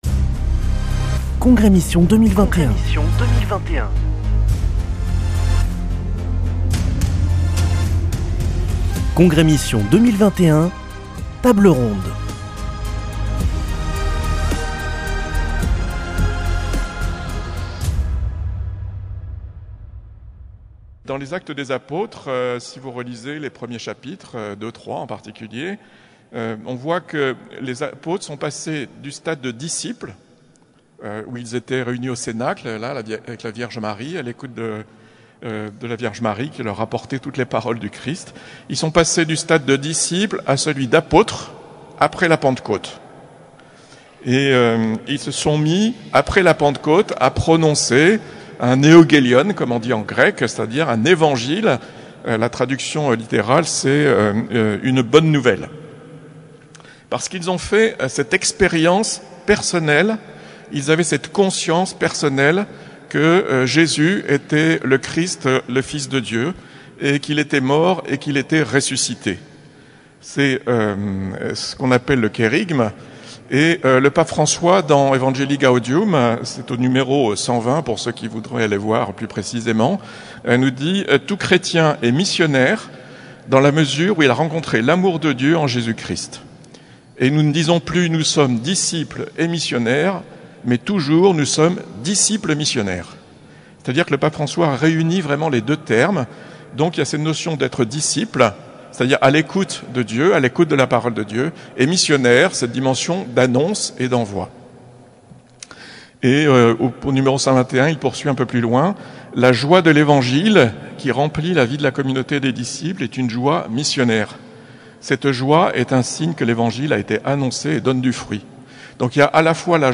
Congrès Mission du 1er au 3 octobre à Toulouse - Table ronde 8